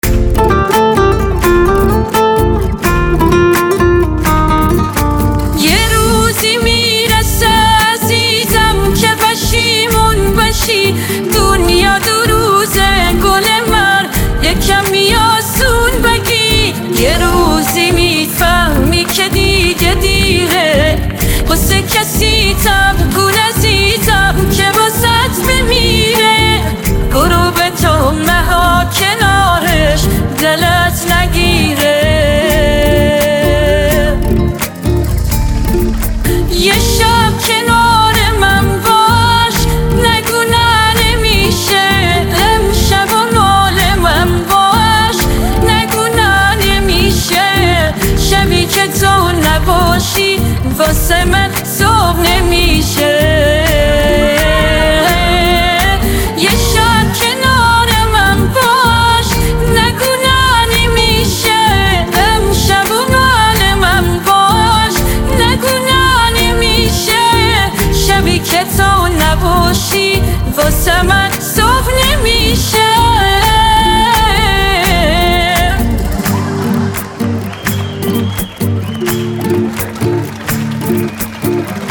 نوستالژی